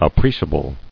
[ap·pre·cia·ble]